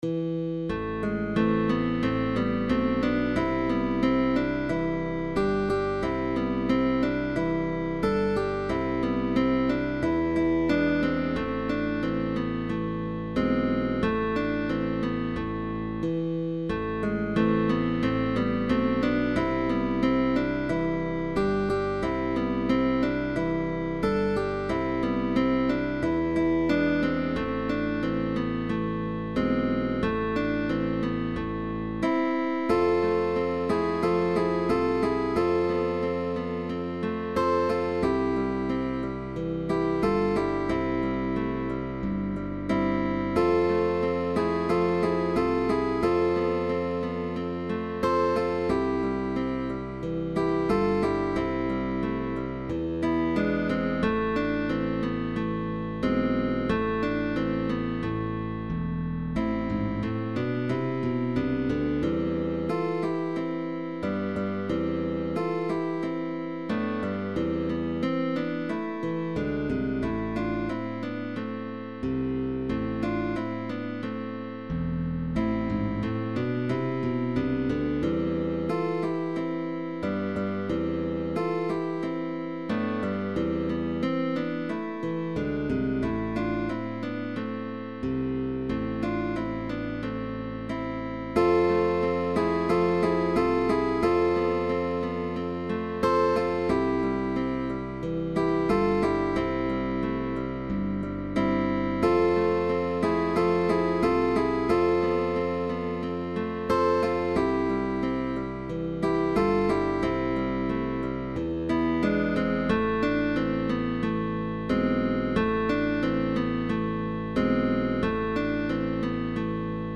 GUITAR TRIO
“Apoyando” and “Tirando”.
With bass optional.